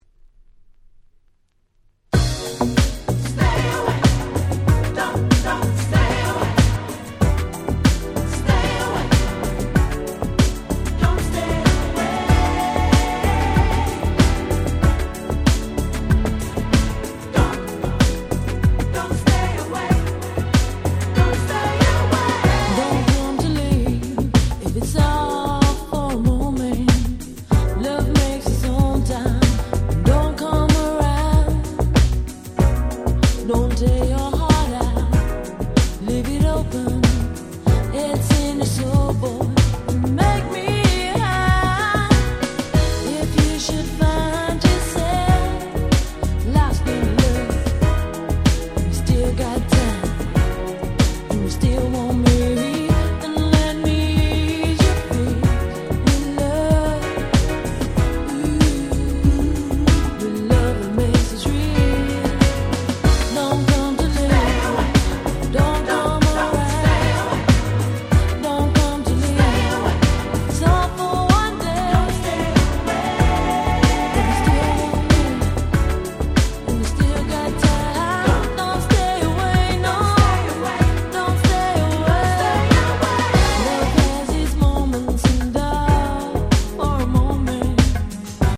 92’ Very Nice UK Soul !!
R&B